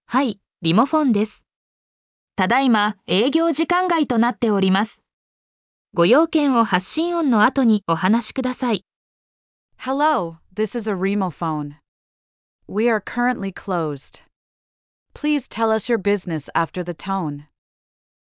英語で留守番電話の応答メッセージを流したい | りもふぉん - ビジネス電話を自由に
音声ファイル作成サイト 音声さん（他社サイト）